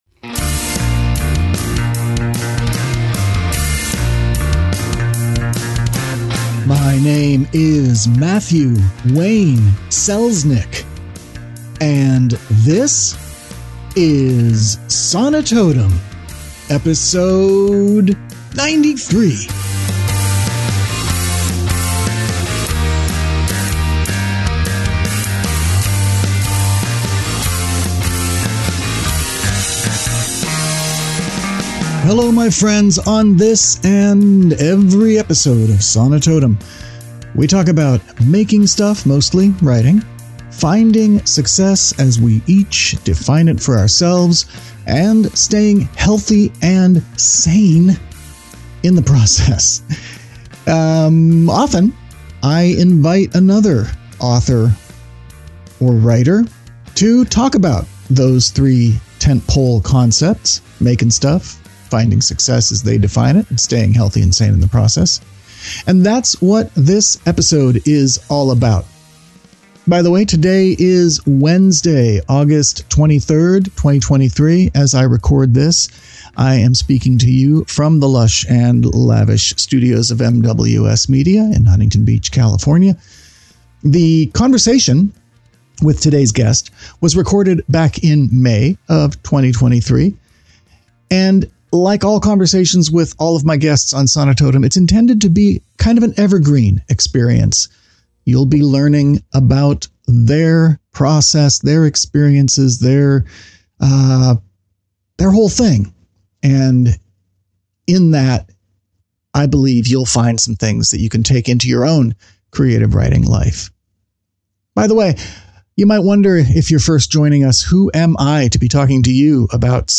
Sonitotum 093: In Conversation w